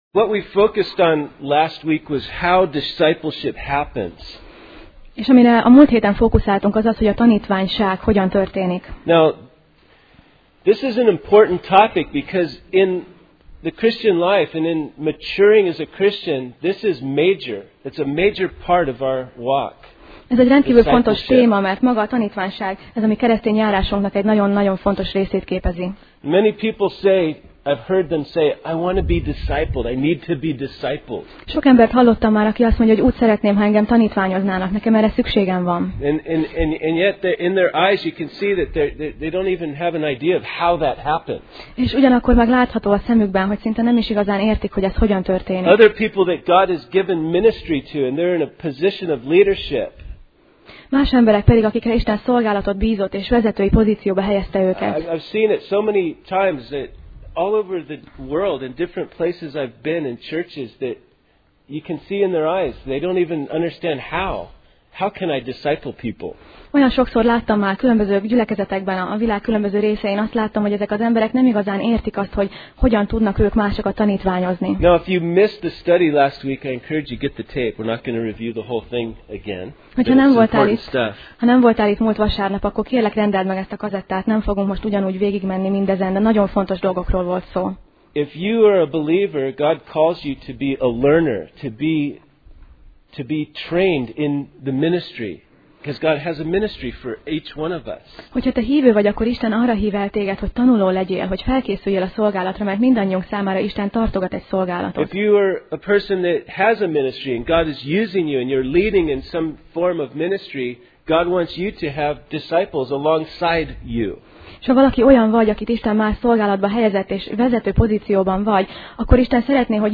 Kolossé Passage: Kolossé (Colossians) 4:10-11 Alkalom: Vasárnap Reggel